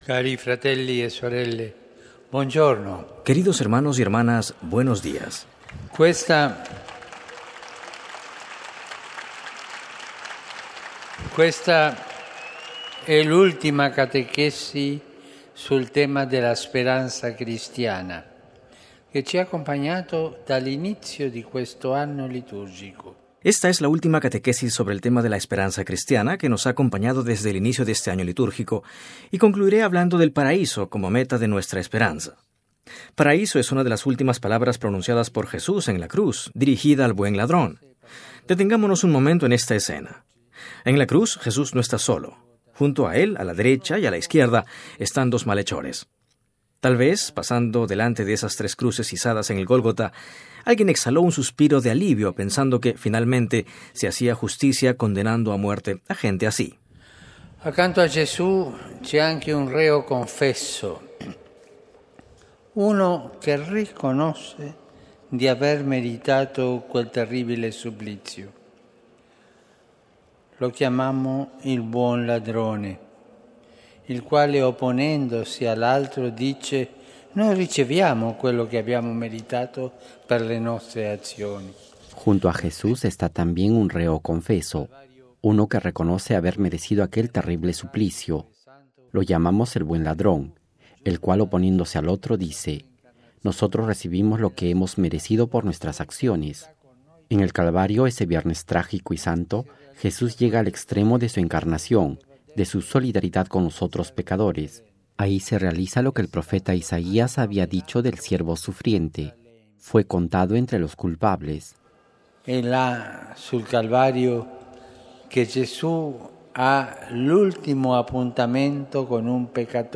“El buen ladrón nos recuerda nuestra verdadera condición ante Dios: que somos sus hijos y que Él viene a nuestro encuentro, teniendo compasión de nosotros. No existe ninguna persona, por muy mala que haya sido en su vida, a la que Dios le niegue su gracia si se arrepiente. Ante Dios nos encontramos todos con las manos vacías, pero esperando en su misericordia”, lo dijo el Papa Francisco en la Audiencia General del último miércoles de octubre, meditando sobre la esperanza cristiana y esta es la última catequesis sobre este tema, señaló el Pontífice, que dedicamos al paraíso como meta de nuestra esperanza.
Texto y audio completo de la catequesis del Papa Francisco